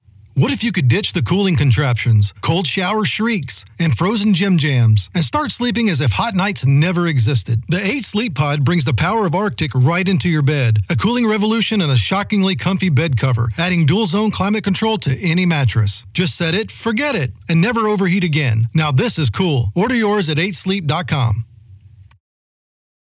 Internet Bedding Advert
Internet Bedding Ad_1.29.25.mp3